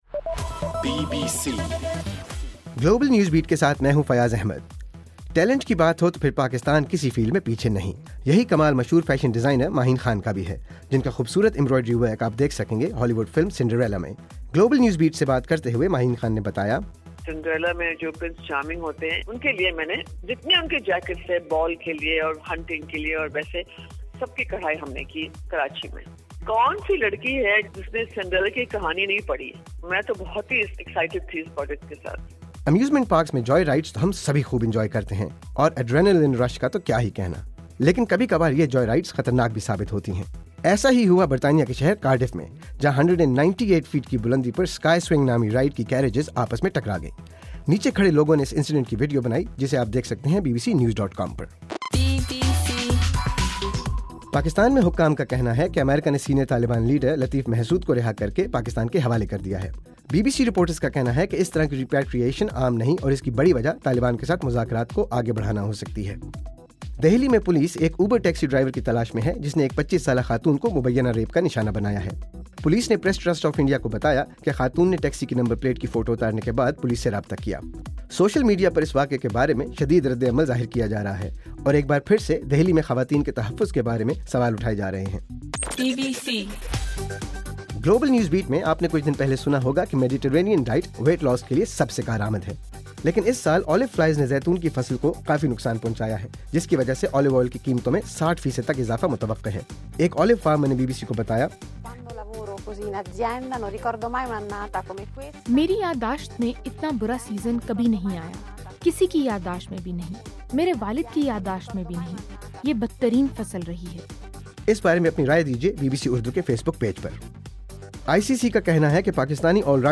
دسمبر 7: رات 8 بجے کا گلوبل نیوز بیٹ بُلیٹن